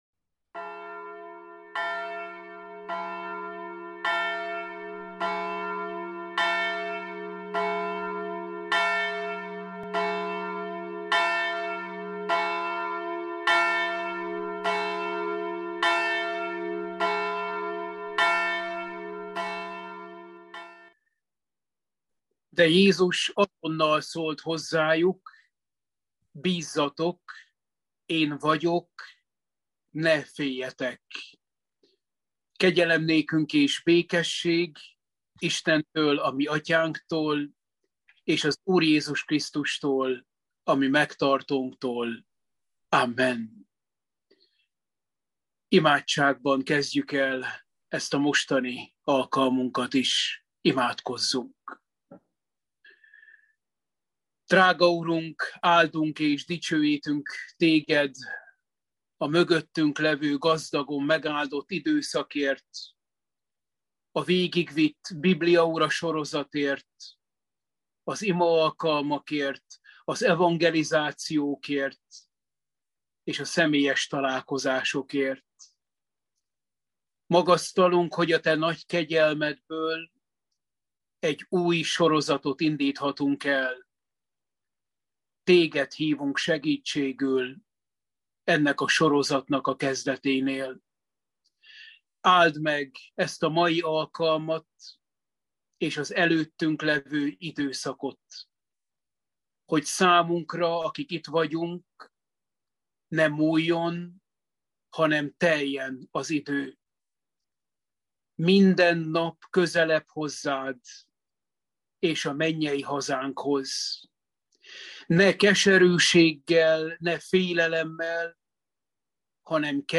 Efézusi levél – Bibliaóra 1